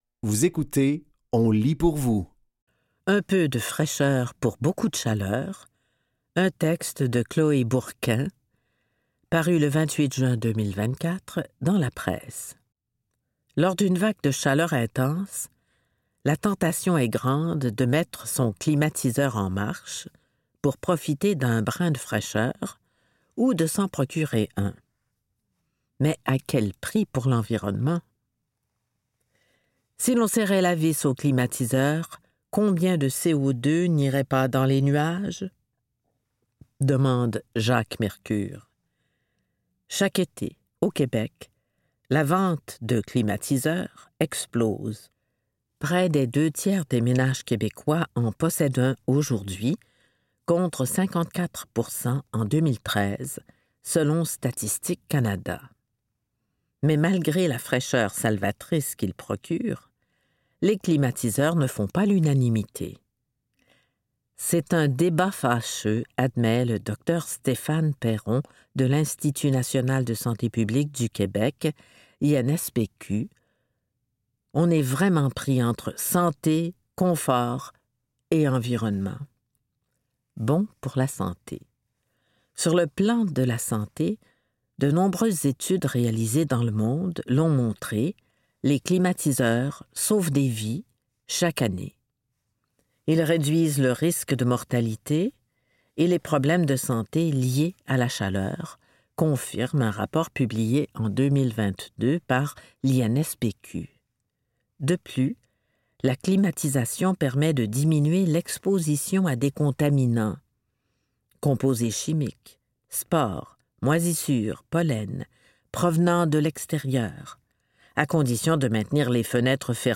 Dans cet épisode de On lit pour vous, nous vous offrons une sélection de textes tirés des médias suivants : La Presse, Québec Science et Le Devoir.